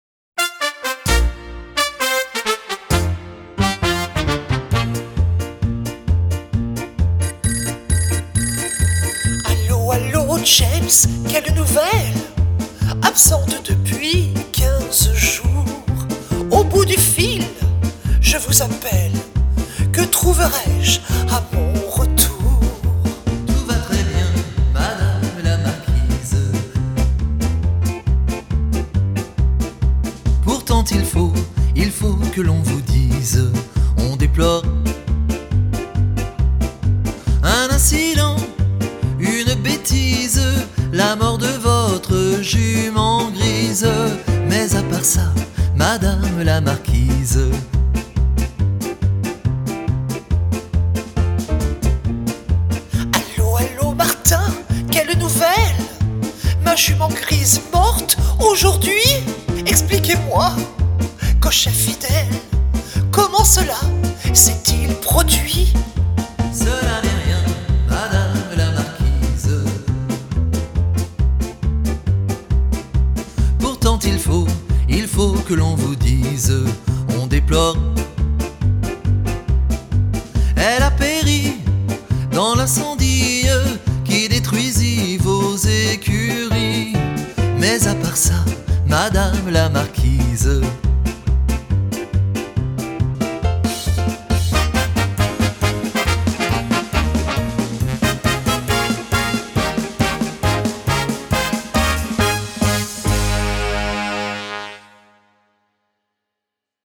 La version chantée avec les trous (Facile)